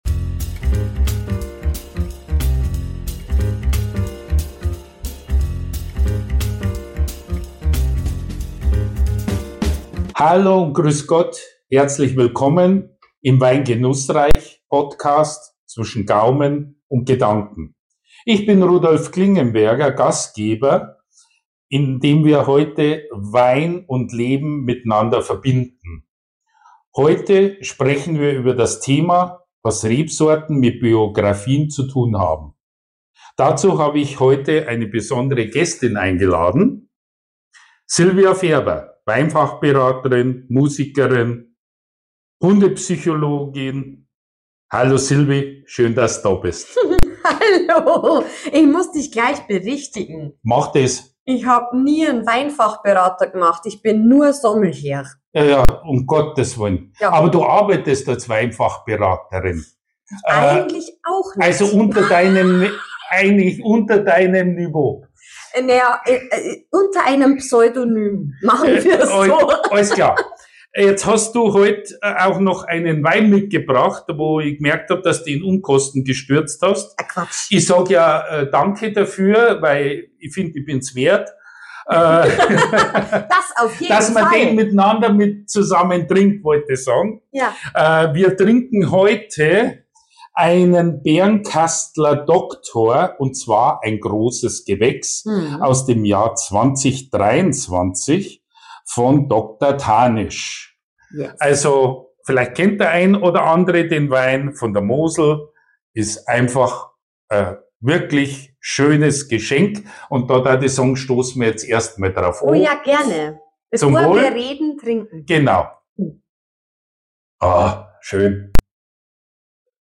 Ein Gespräch über Intuition, Erfahrung, Wandel und Selbstbeobachtung.